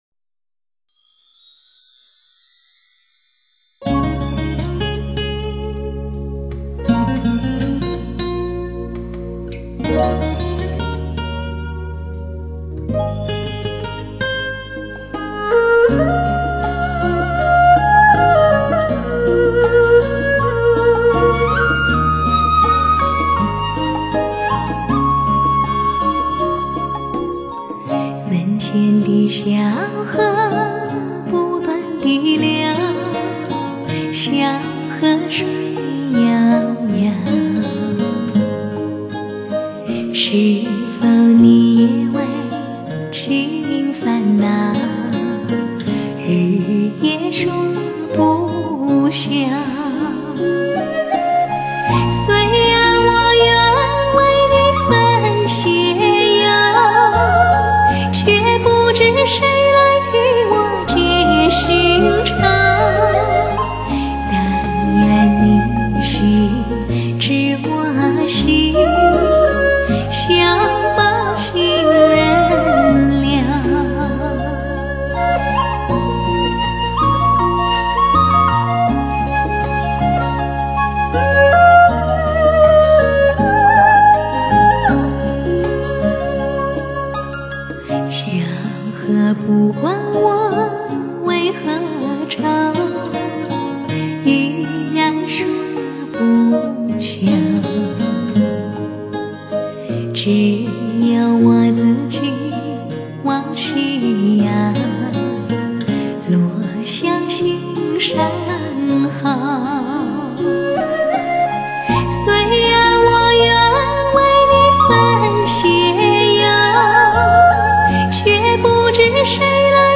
喜欢她那温婉的声音，像古时的眉线，或细如柳叶、或高挑妩媚，听她的歌应该在晚上，寂寞的夜里，让音乐去抚平白天的喧嚣和噪杂。